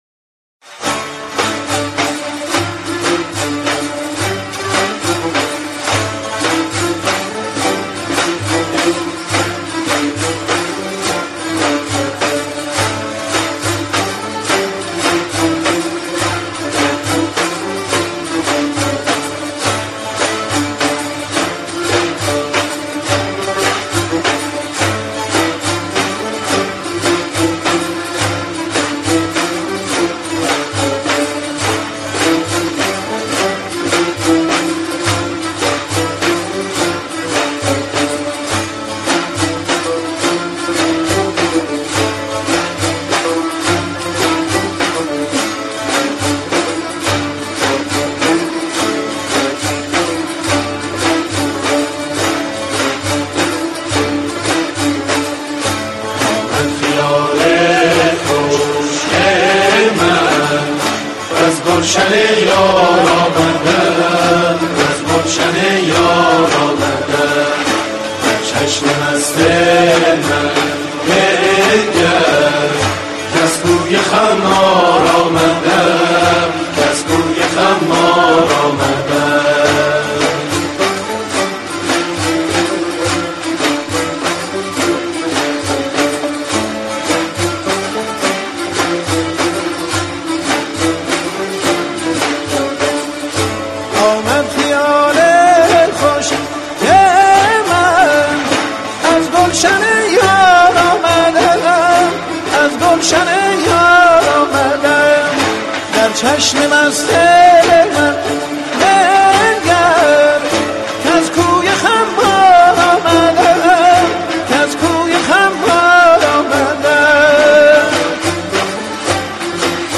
در مقام راست پنجگاه